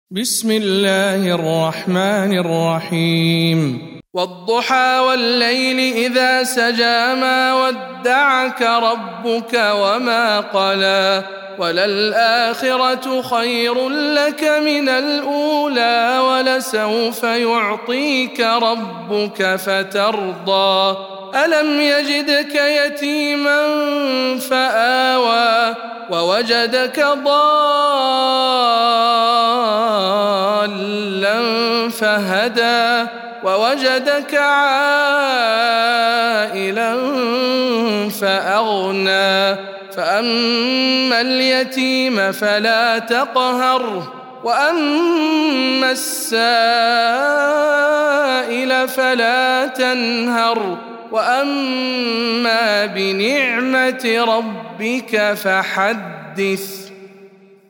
سورة الضحى - رواية هشام عن ابن عامر